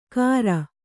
♪ kāra